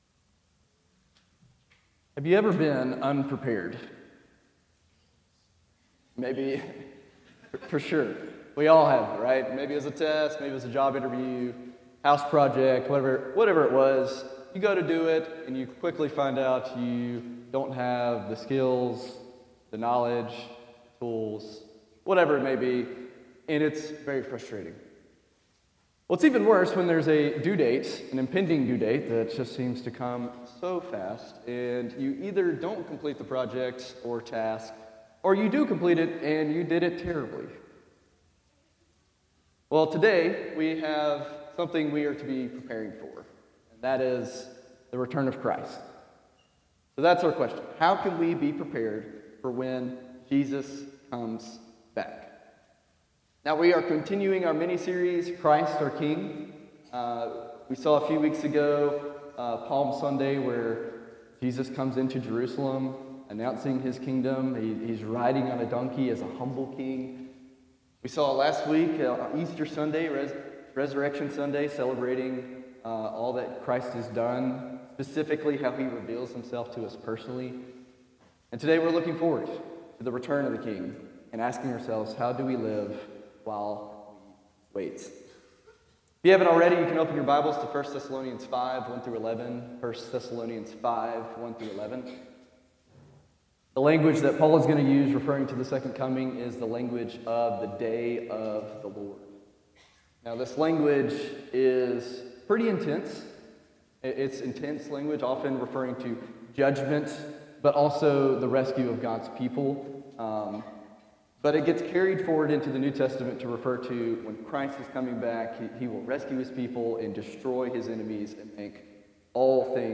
Preacher
Christ Our King Passage: 1 Thessalonians 5 1-11 Service Type: Sunday Morning « Revelation of the King Before You Share Your Faith